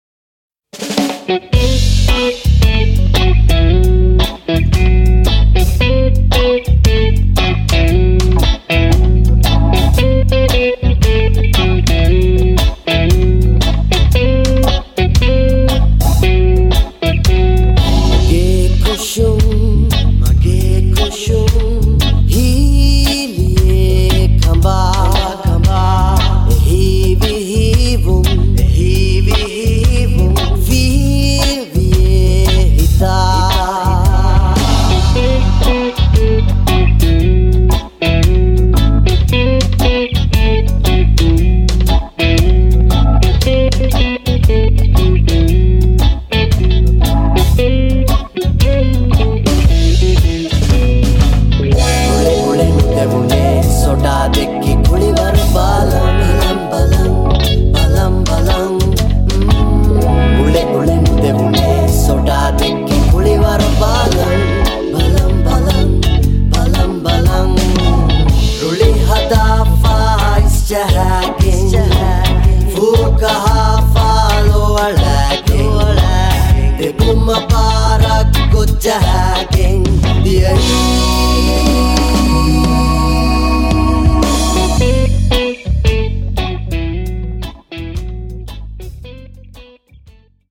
background vocals
Organ, Piano & Rhodes
guitars
Drums & Percussion
Trumpet
Tenor Sax
Trombone